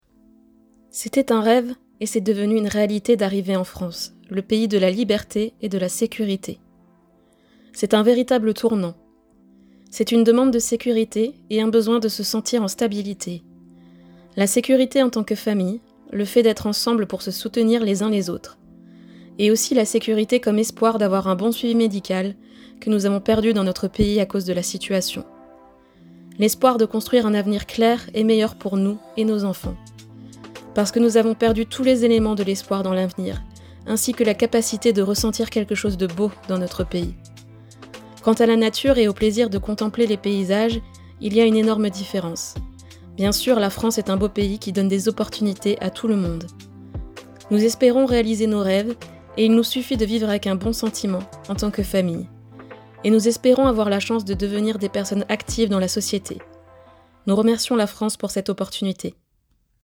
Et pour terminer leur réalisation, les résidents participants ont enregistré des témoignages en lien avec leur portrait ou bien leur nature morte, en français mais également dans leur langue maternelle pour ceux issus d’autres pays (témoignages en ukrainien, en albanais ou en arabe).